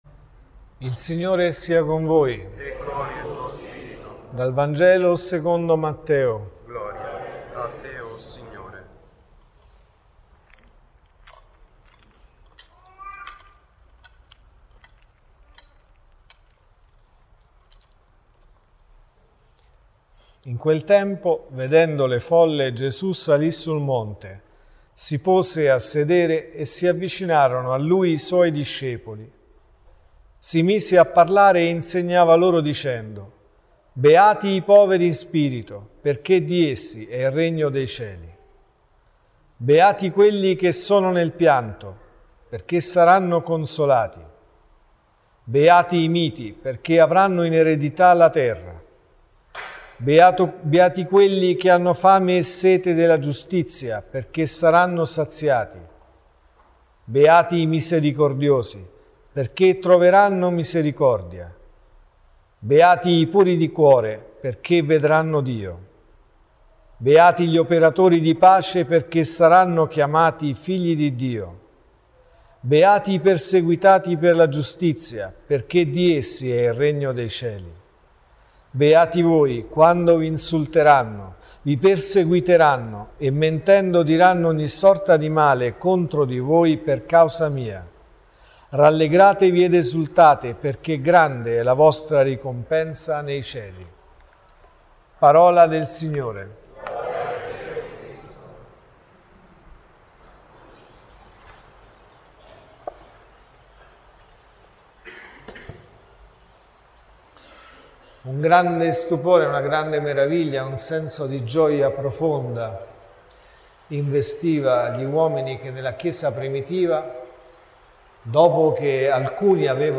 Omelie